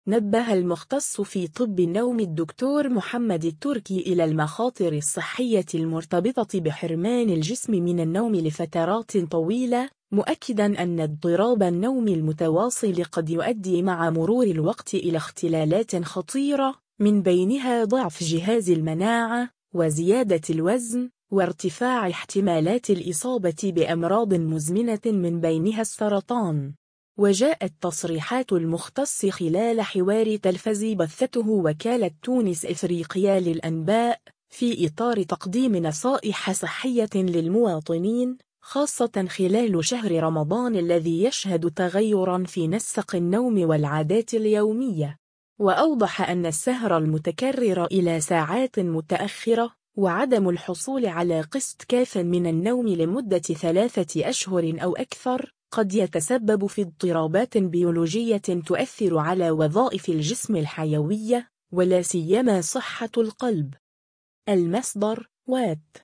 وجاءت تصريحات المختص خلال حوار تلفزي بثّته وكالة تونس إفريقيا للأنباء، في إطار تقديم نصائح صحية للمواطنين، خاصة خلال شهر رمضان الذي يشهد تغيرًا في نسق النوم والعادات اليومية.